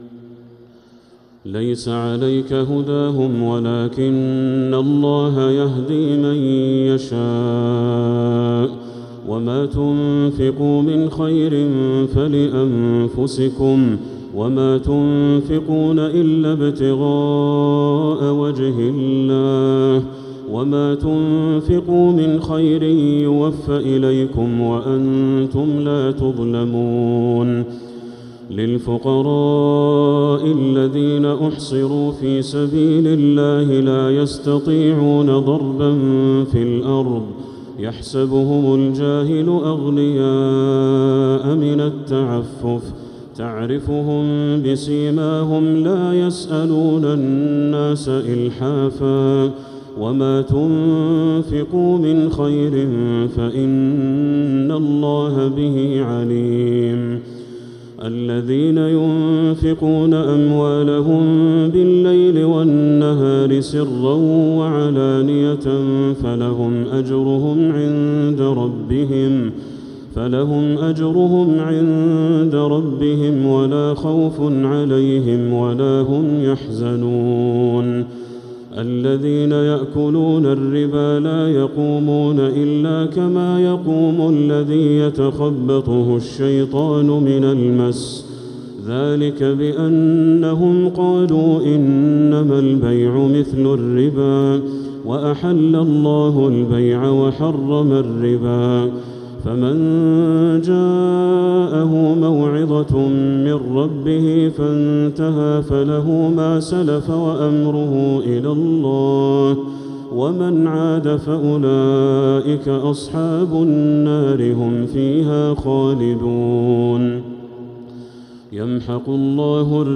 تراويح ليلة 4 رمضان 1447هـ من سورتي البقرة {272 -286} و آل عمران {1-41} Taraweeh 4th night Ramadan 1447H Surah Al-Baqara Surah Aal-i-Imraan > تراويح الحرم المكي عام 1447 🕋 > التراويح - تلاوات الحرمين